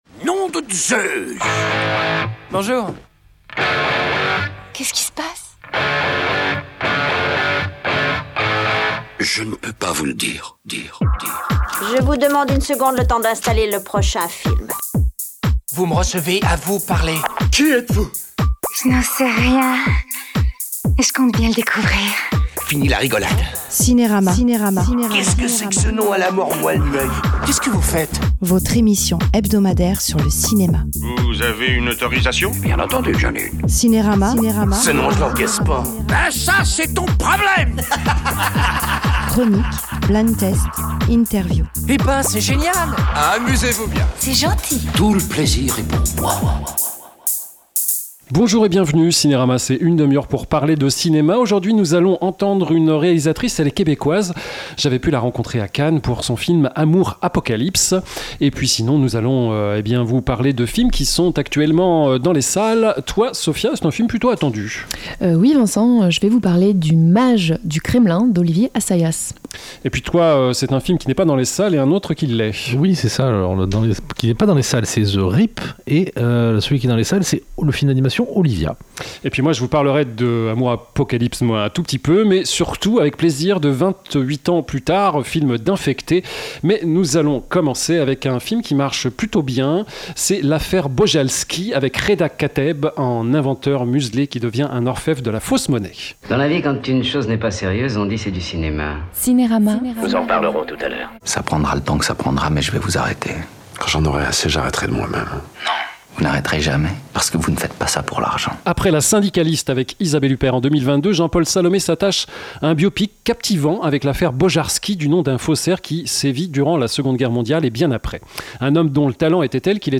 L’INTERVIEW
LES CHRONIQUES Le Mage du Kremlin / Amour Apocalypse / Olivia / L’Affaire Bojarski / 28 Ans Plus Tard : Le Temple Des Morts / The Rip Ecoutez l’émission en podcast : CINERAMA Cinérama, une émission hebdomadaire sur le cinéma produite par Radio Divergence.